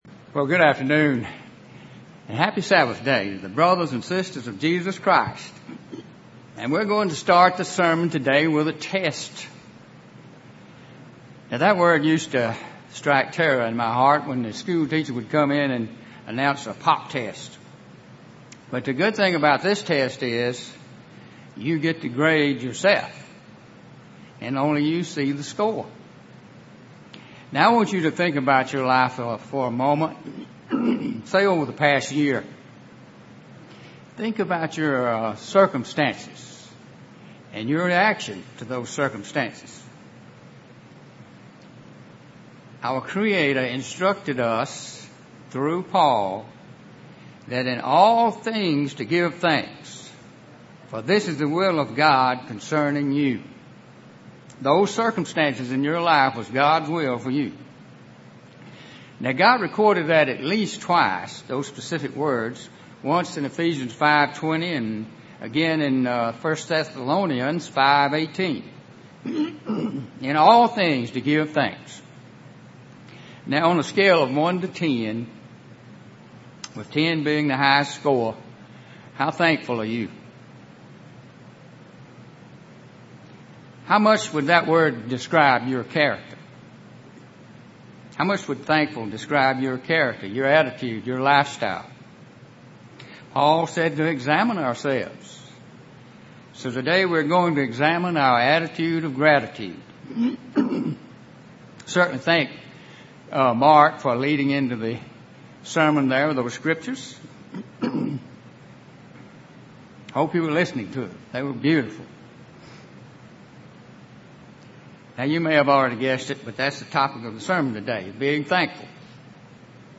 A powerful sermon on the vital importance of cultivating a daily lifestyle of thankfulness towards God.